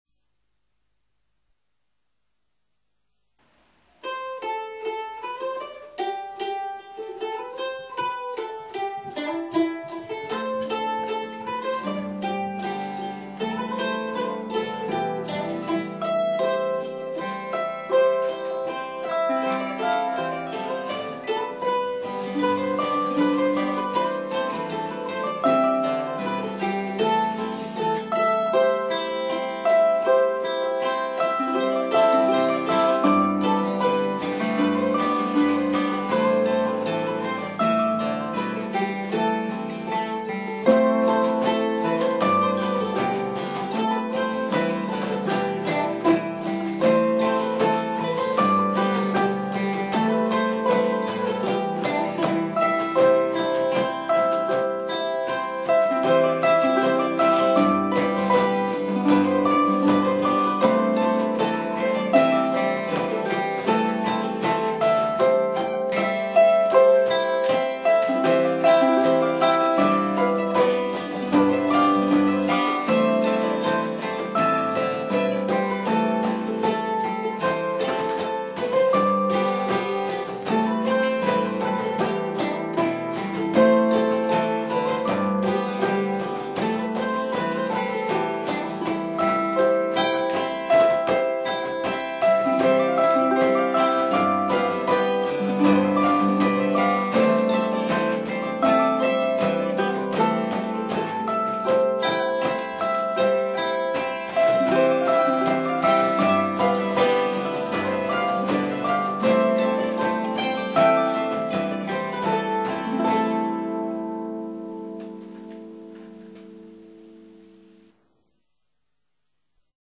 Recorded at Flying Fiddle Studio
Harp
Mandolin
Guitar
Bodhran